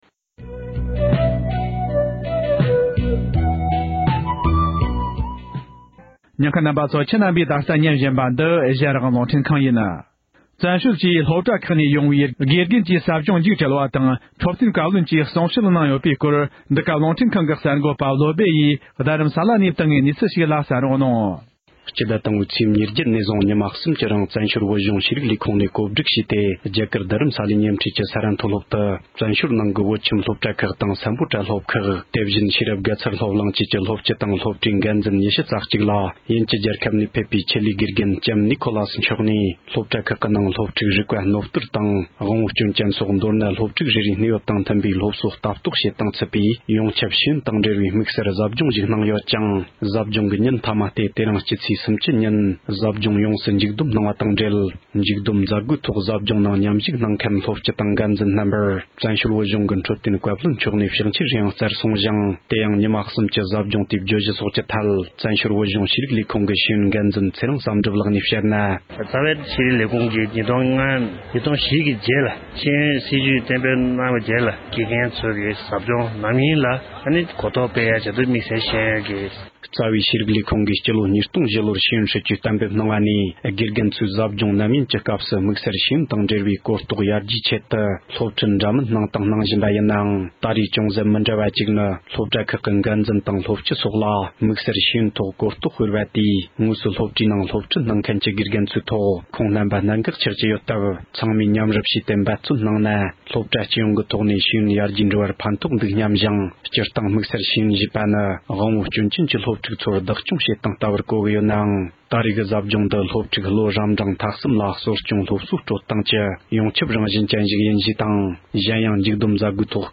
འཕྲོད་བསྟེན་བཀའ་བློན་ནས་ཟབ་སྦྱོང་མཇུག་སྒྲིལ་གྱི་གསུང་བཤད།
སྒྲ་ལྡན་གསར་འགྱུར།